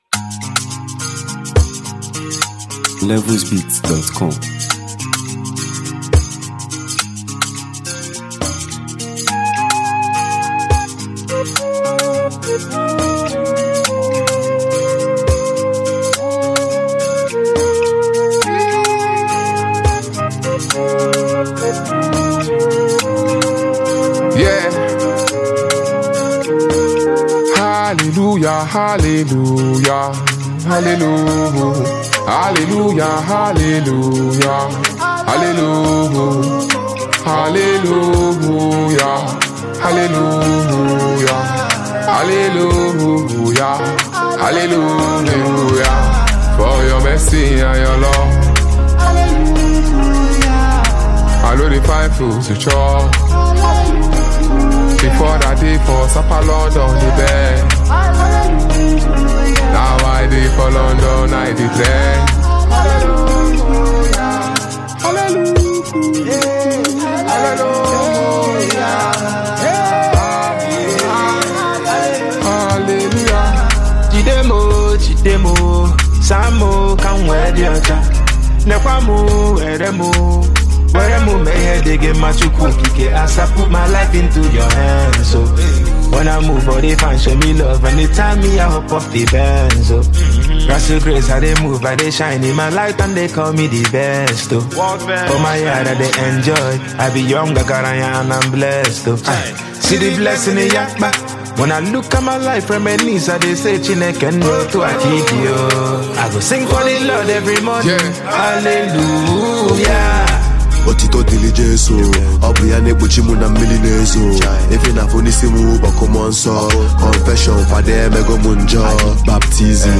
spiritually charged and electrifying